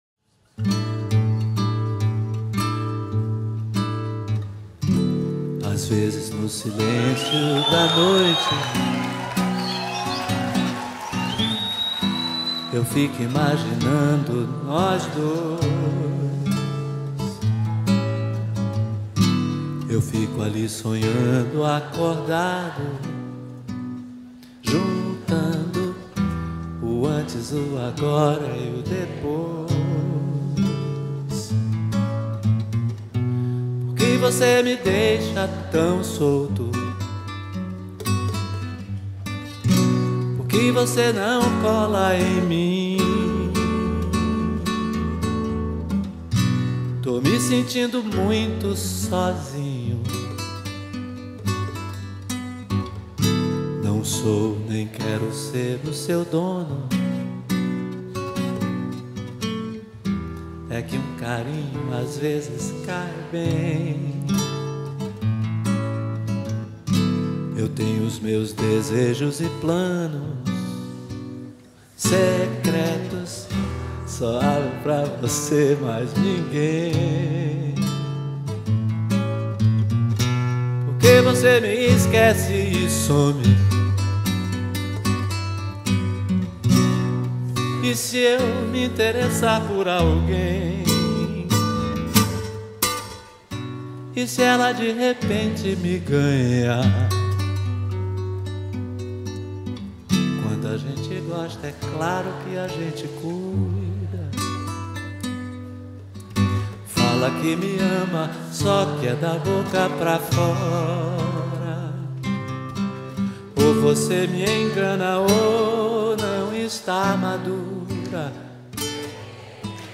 2025-02-15 23:02:38 Gênero: MPB Views